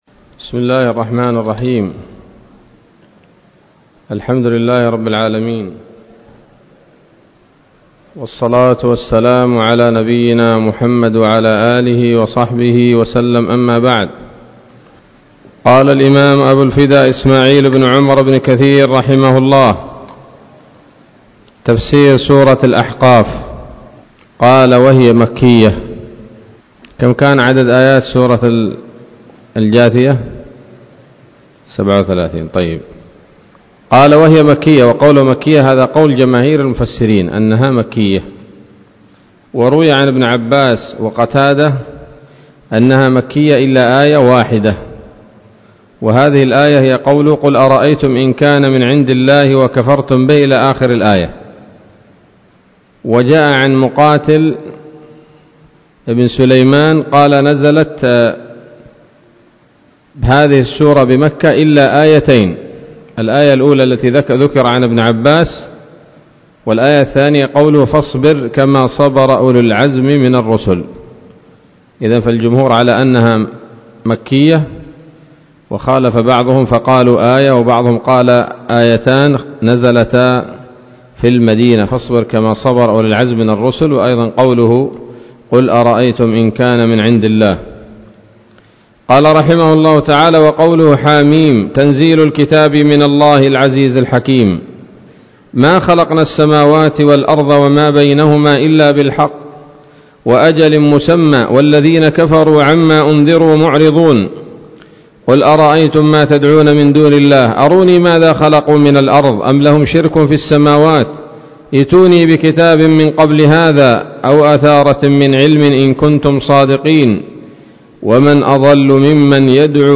الدرس الأول من سورة الأحقاف من تفسير ابن كثير رحمه الله تعالى